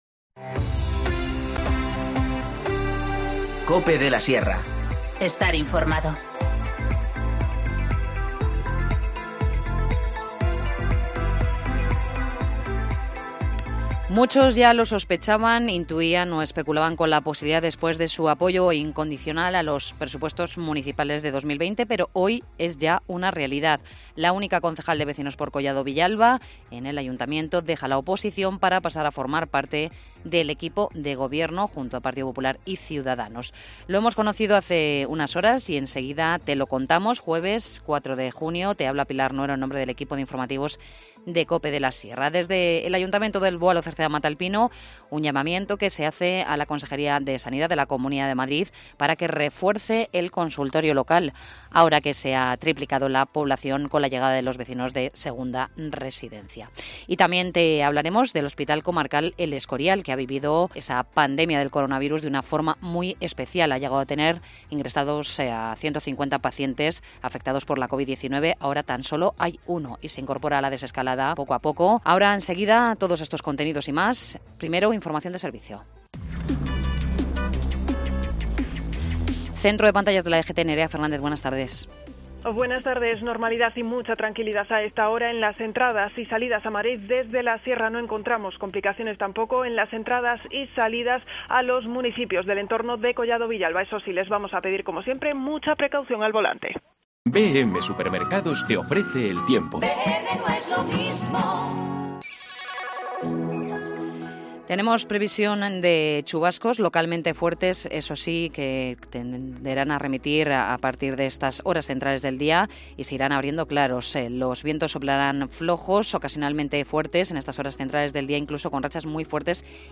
Informativo Mediodía 4 junio 14:20h